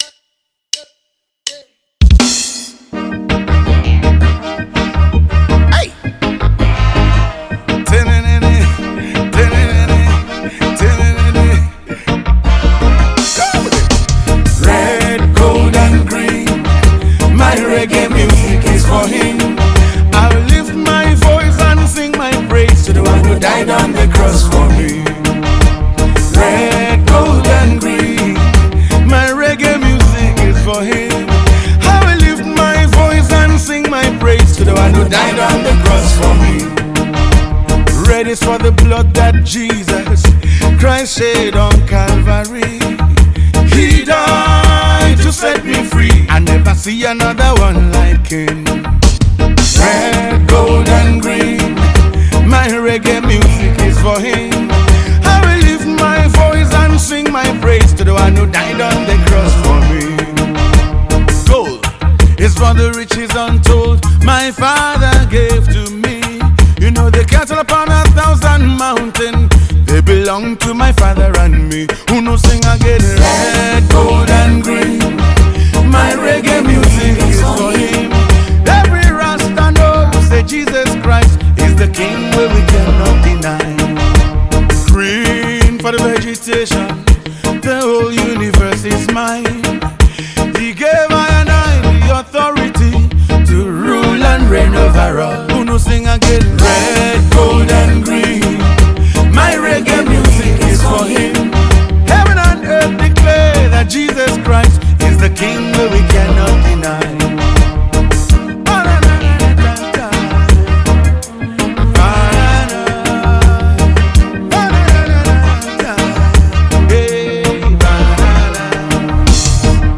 Gospel
poet spoken words and reggae gospel artist.